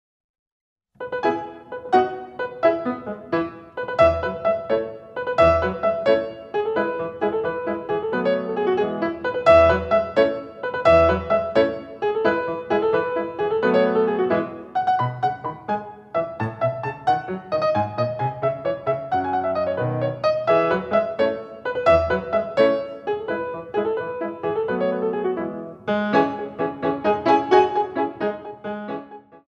Battements Frappes